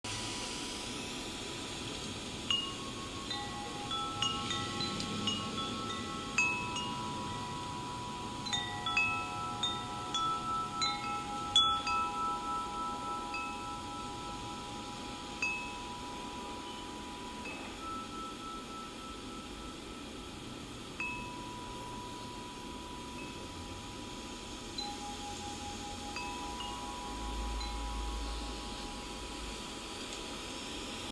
Location: On my back porch near my wind chimes
Sounds: The wind blowing by, the chimes clanking creating beautiful tunes, the ruffling of the trees from the wind